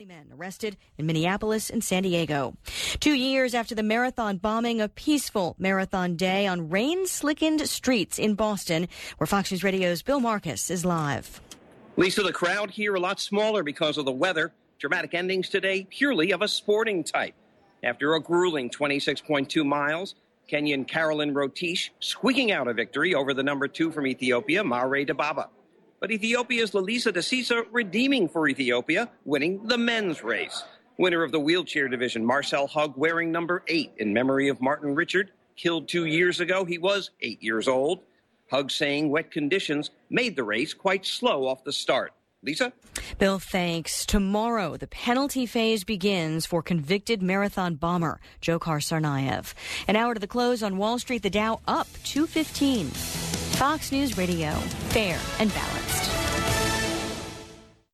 (BOSTON) APRIL 20 – 3PM LIVE